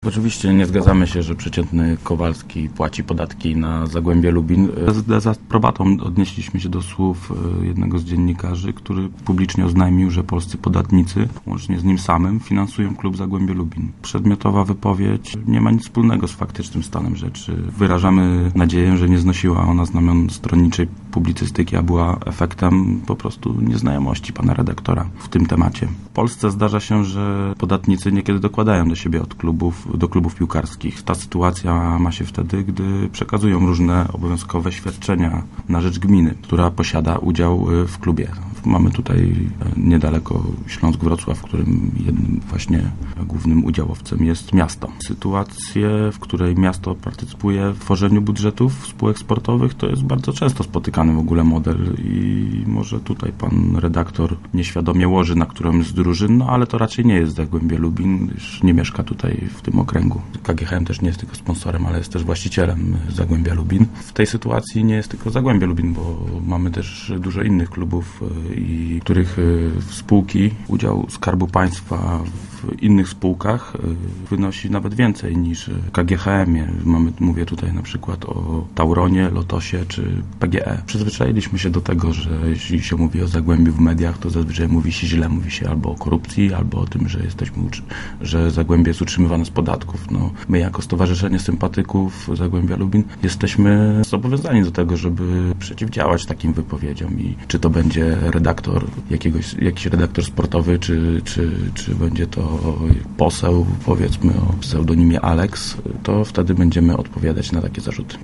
którego gościliśmy w Rozmowach Elki.